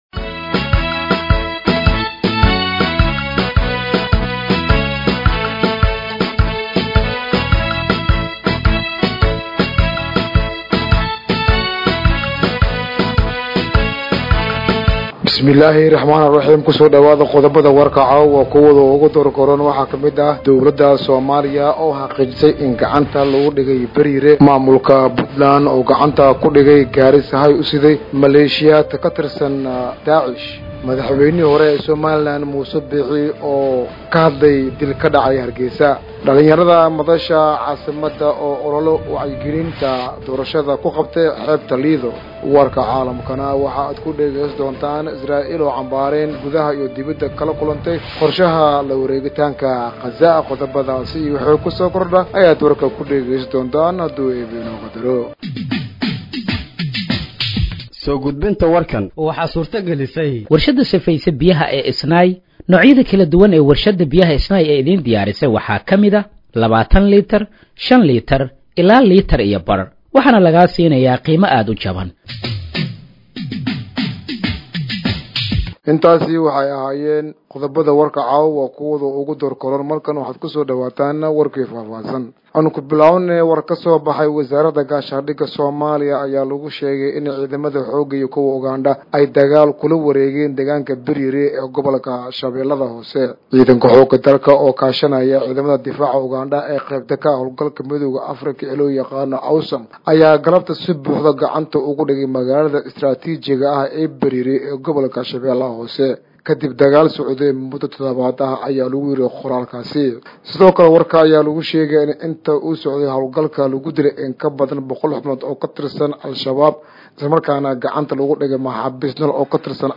Dhageeyso Warka Habeenimo ee Radiojowhar 08/08/2025